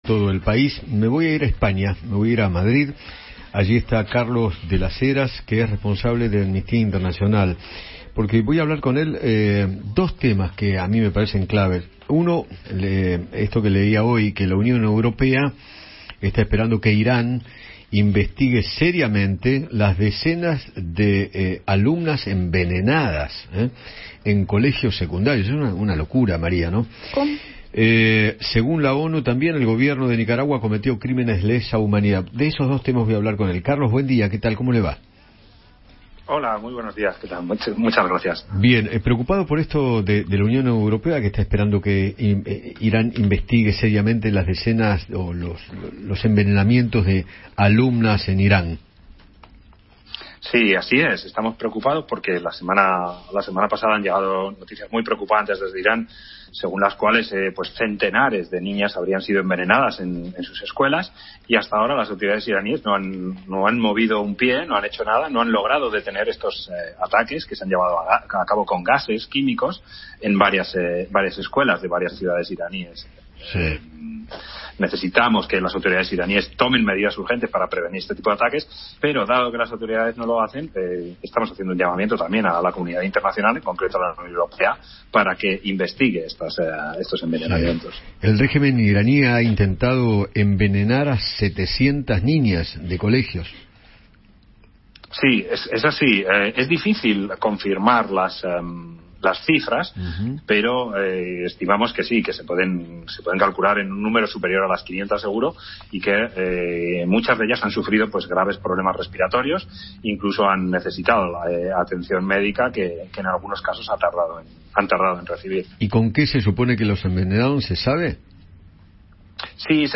El relato de un responsable de Amnistia Internacional: "Ortega pretende cambiar la prisión injusta por el exilio forzado” - Eduardo Feinmann